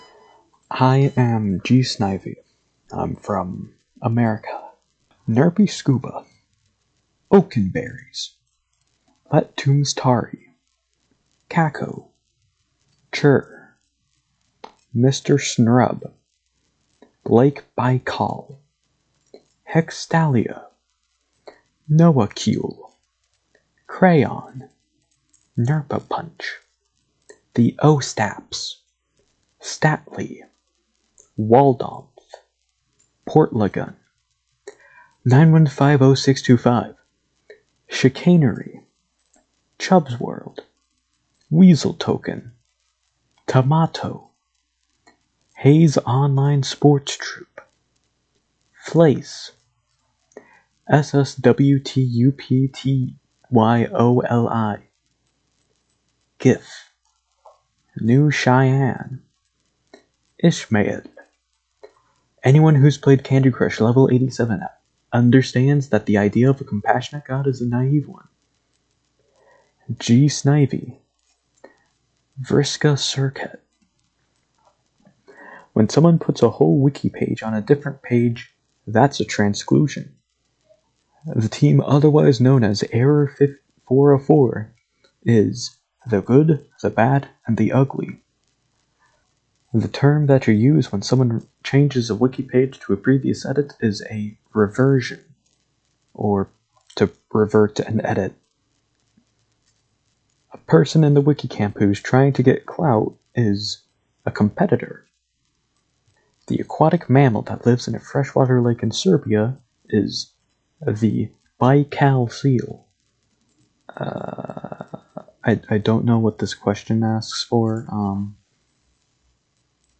Recordings of ourselves answering those questions.